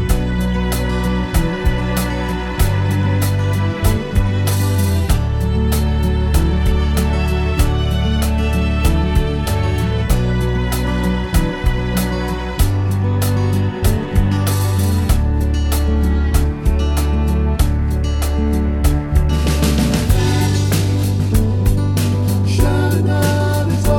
One Semitone Down Pop (2010s) 3:52 Buy £1.50